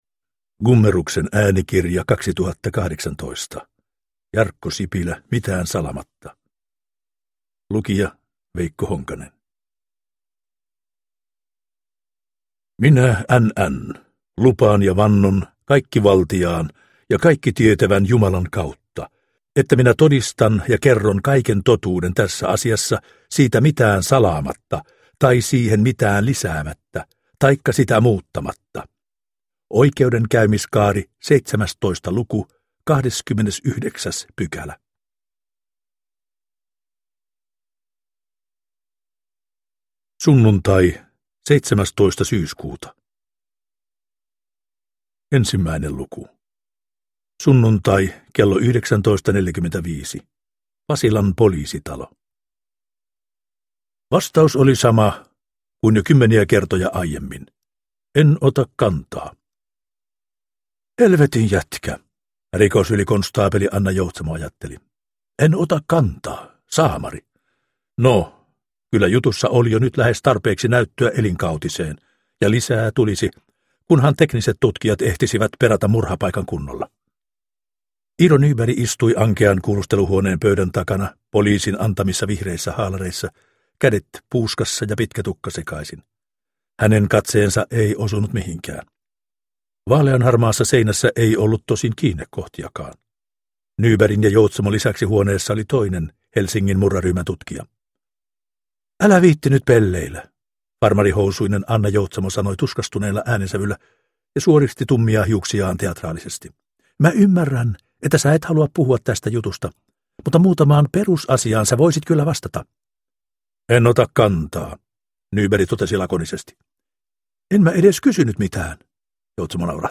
Mitään salaamatta – Ljudbok – Laddas ner